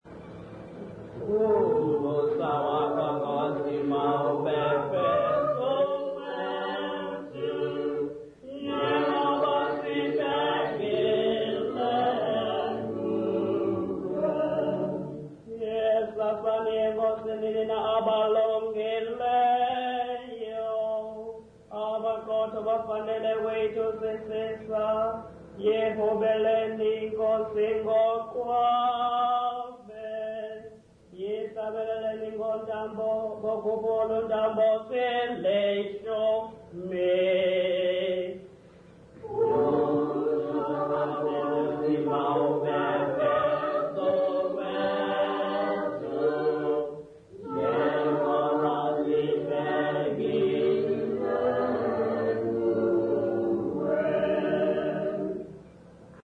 Intshanga church music workshop participants
Sacred music South Africa
Folk music South Africa
Hymns, Zulu South Africa
field recordings
Unaccompanied church hymn.